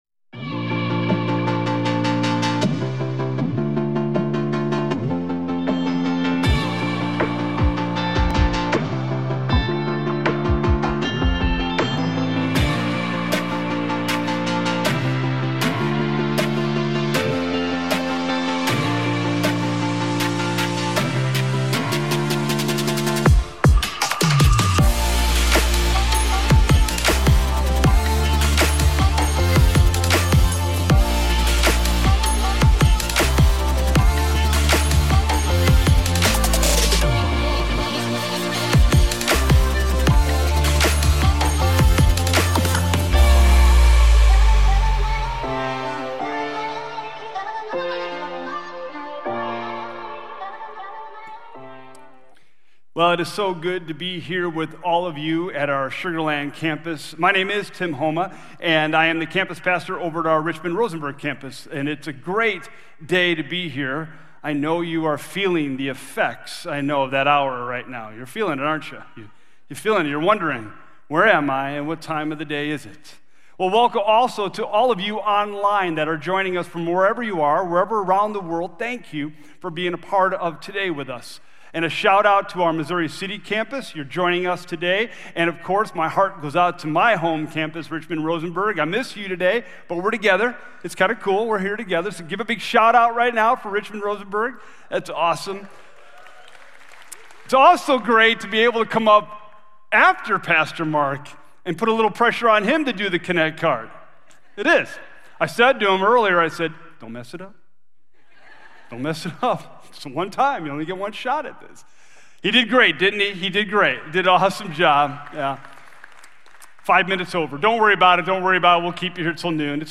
Here you can watch Sugar Creek sermons live online, or look back at previous sermons.